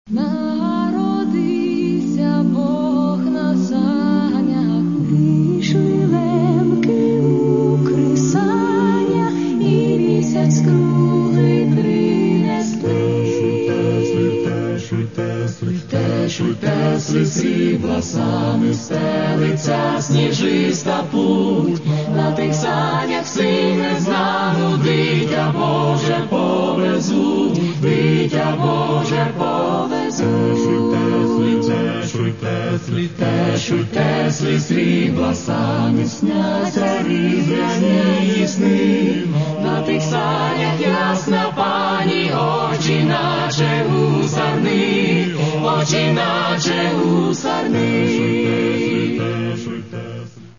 Каталог -> Інше -> Вокальні колективи
Крім того, не всі композиції виконано акапельно.